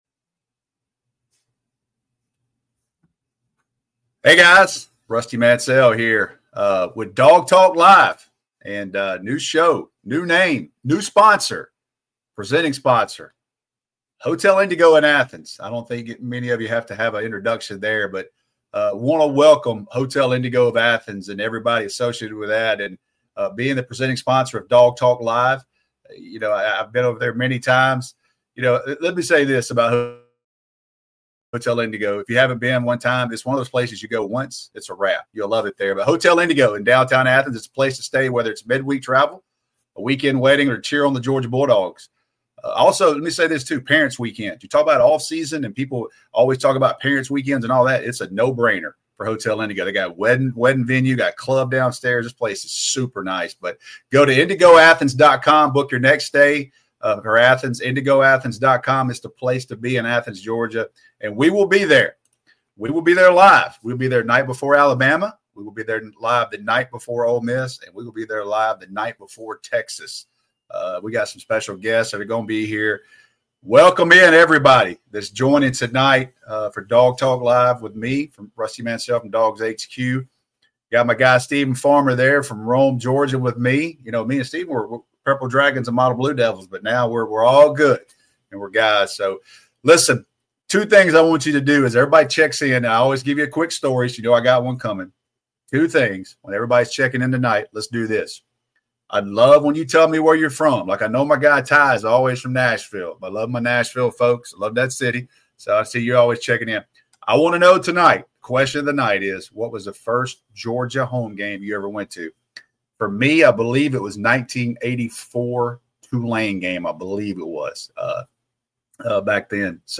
going one-on-one with Georgia fans